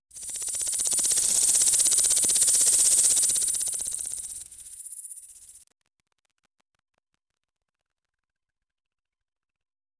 Cyber-samurai, retro-futuristic, cold metal, hydraulic joints, vacuum-adapted, Japanese minimalism, breathing apparatus, HUD interface, armor plating 0:47 UI sound effect: planting a cybernetic seed. Quick, synthesized 'plink' followed by a brief, shimmering 'activation' sound.
ui-sound-effect-planting--y2f4kk2w.wav